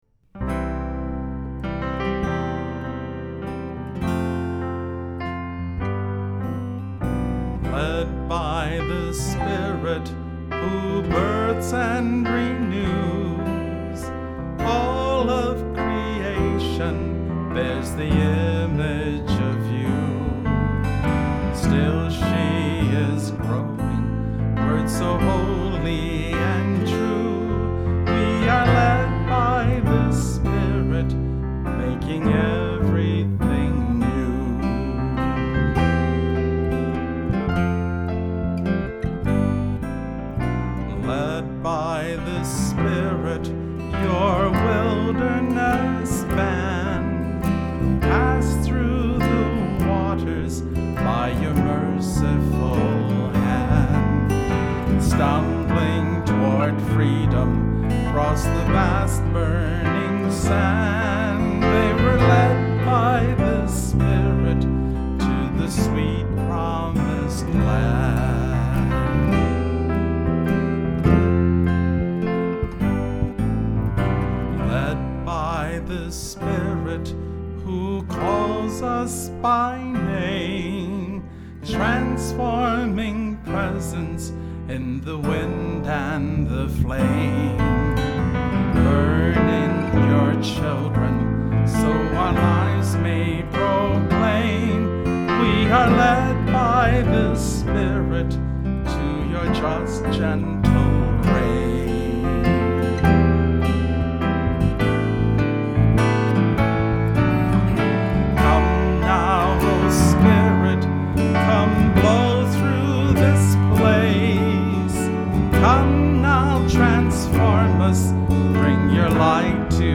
Accompaniment:      Keyboard
Music Category:      Christian
This simple choral arrangement allows the story to shine.